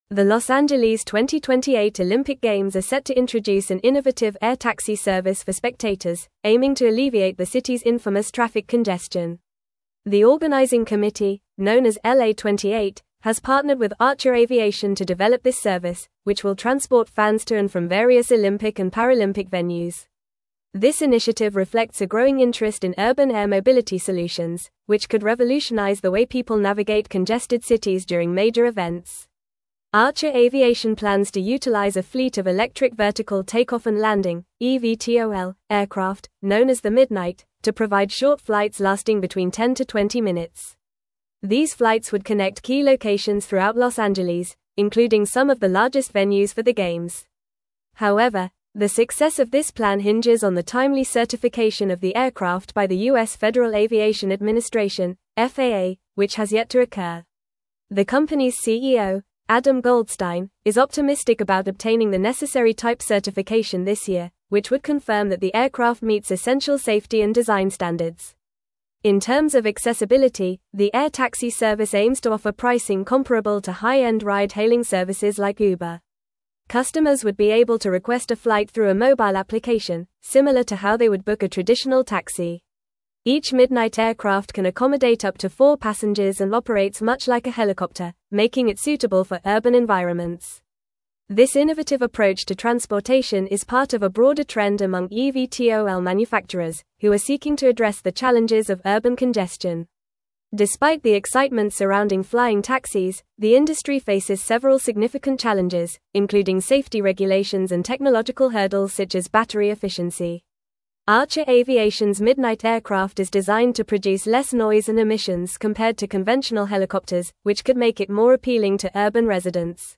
Fast
English-Newsroom-Advanced-FAST-Reading-LA-2028-Olympics-Introduces-Innovative-Air-Taxi-Service.mp3